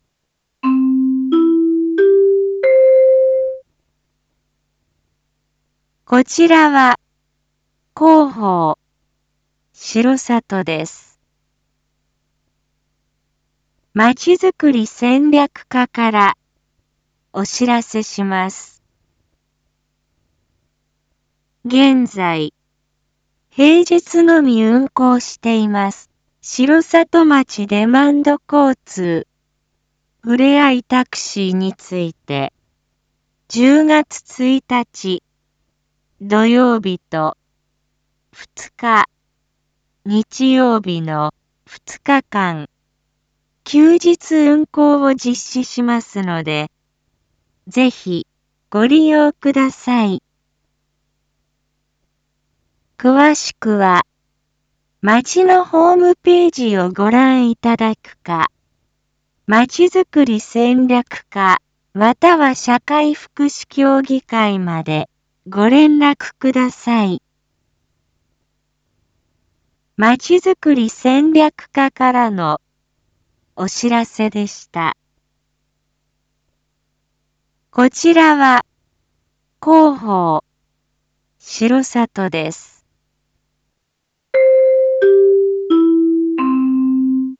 Back Home 一般放送情報 音声放送 再生 一般放送情報 登録日時：2022-09-29 19:01:29 タイトル：R4.9.29 19時放送分 インフォメーション：こちらは広報しろさとです。